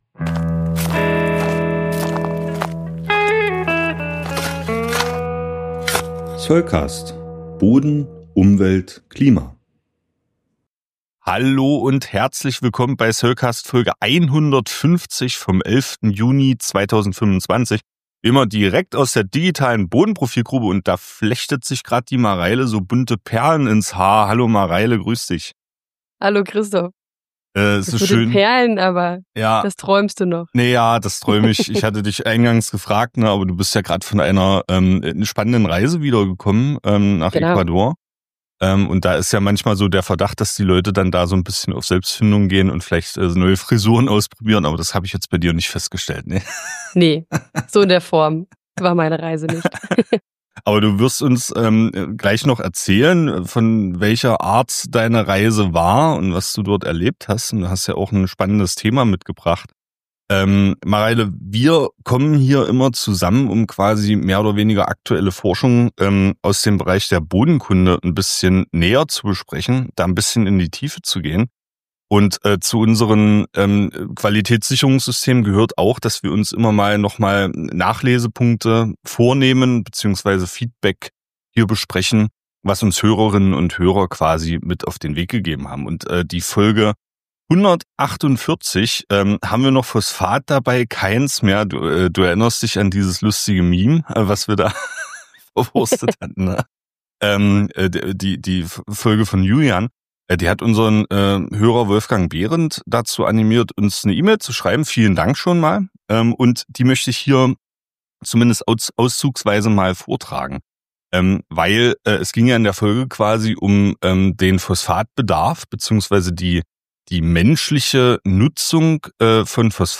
SC143 Interview: Torf-Ersatz im Blumenbeet – Soilcast – Podcast